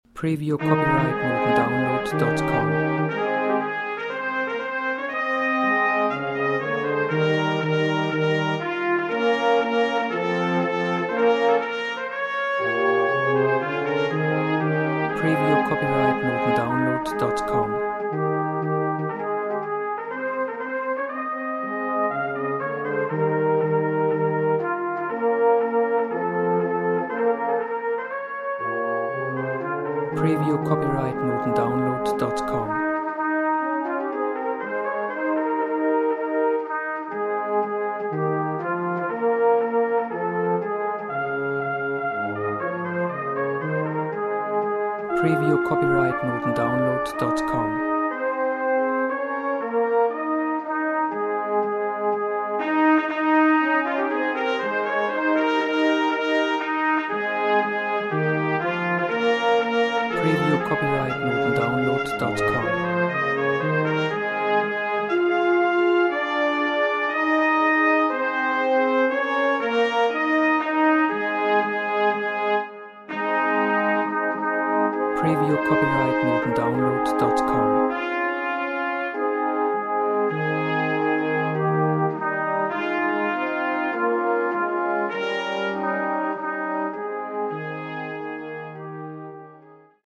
Gattung: für Trompete, Horn und Posaune
Besetzung: Ensemblemusik für 3 Blechbläser